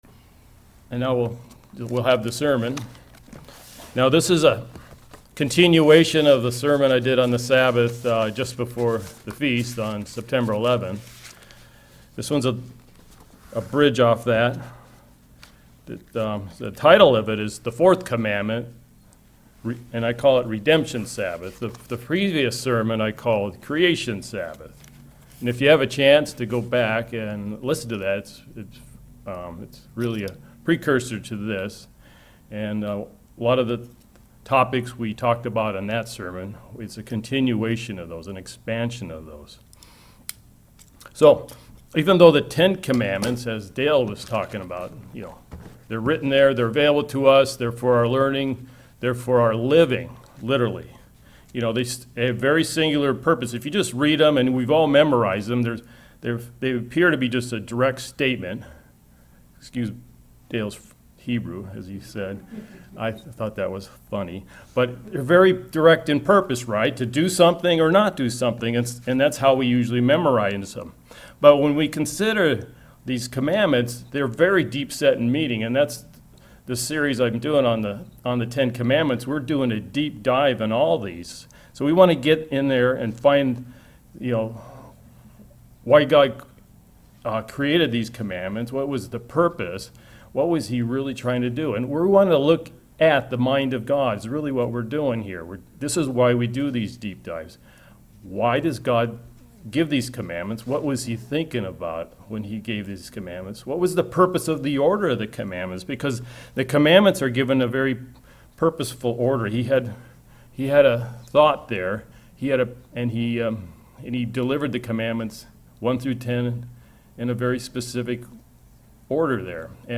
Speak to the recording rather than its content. Given in Kennewick, WA Chewelah, WA Spokane, WA